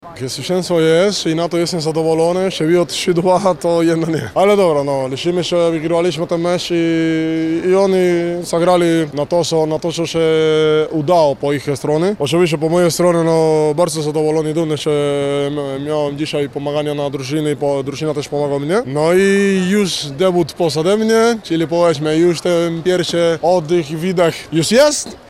• mówił po spotkaniu przyjmujący Bogdanki LUK Lublin Wilfredo Leon.
Wilfredo-Leon-po-Treflu.mp3